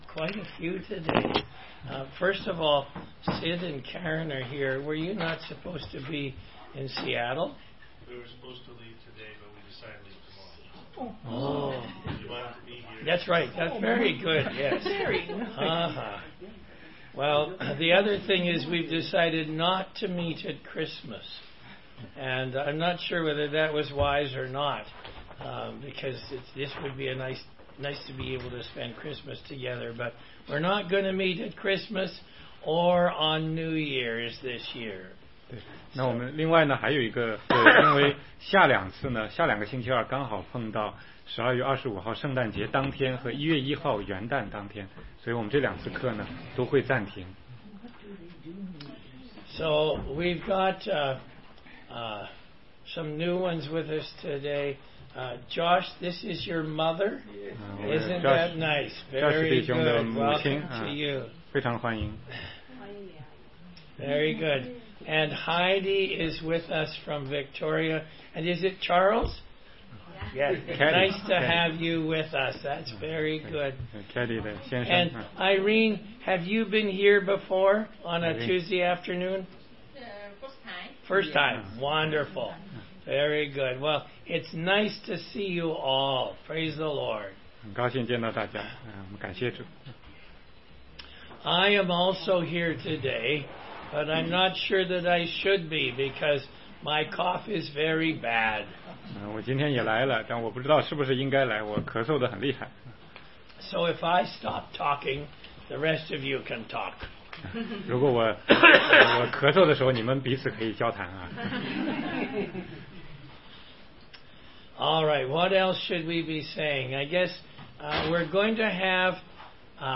16街讲道录音 - 使徒行传26章18节-27章